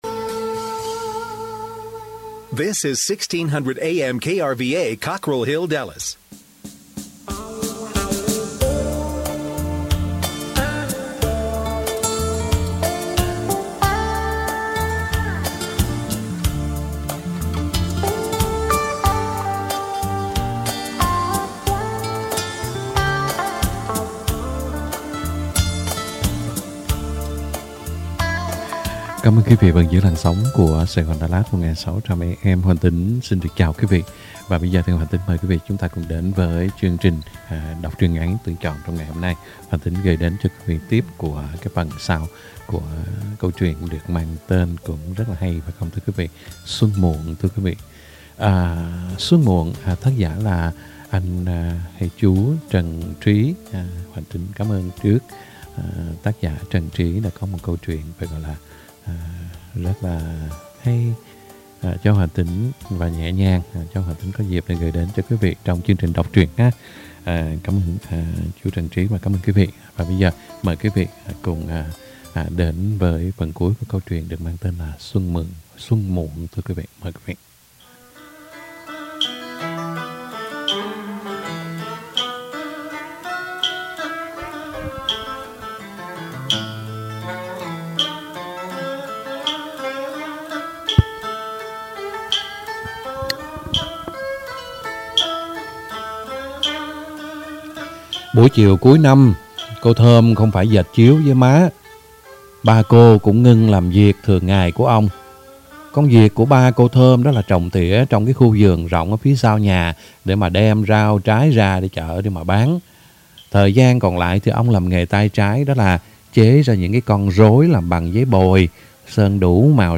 Đọc Truyện Ngắn = Xuân Muộn (2 end) - 03/01/2022 . | Radio Saigon Dallas - KBDT 1160 AM